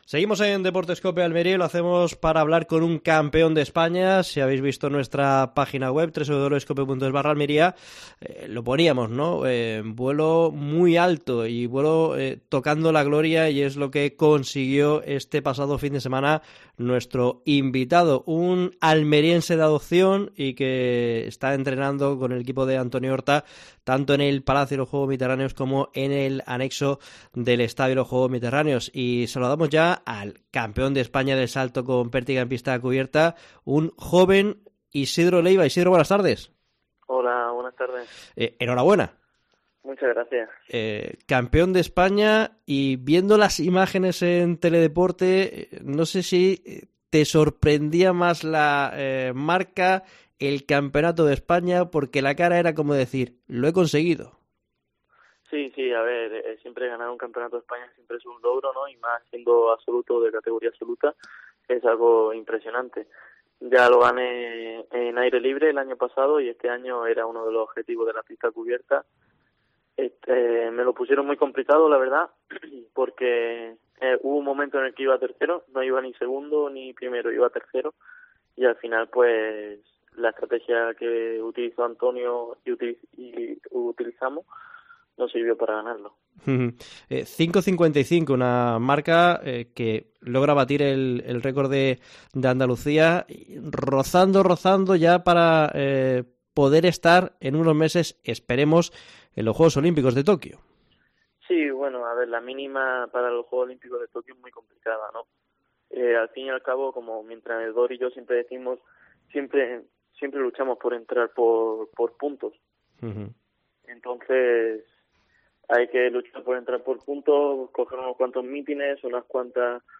Entrevista en Deportes COPE Almería.